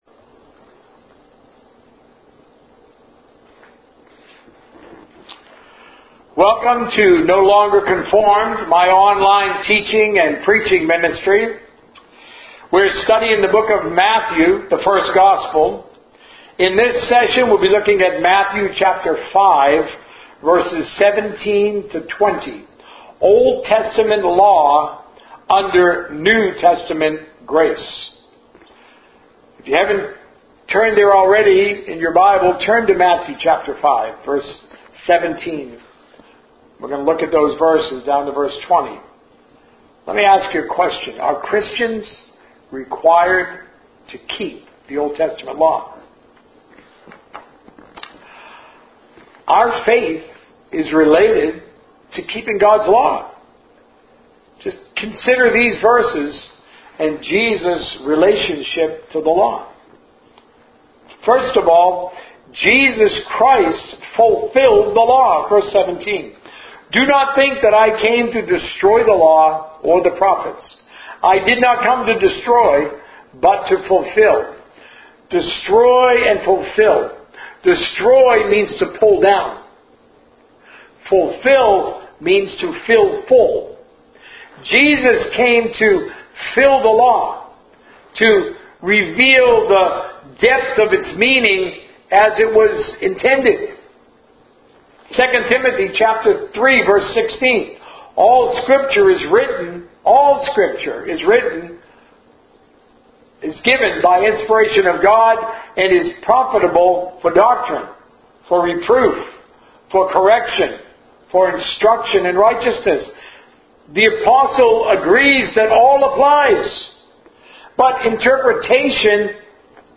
A message from the series "The First Gospel." Marriage and Divorce - Part 1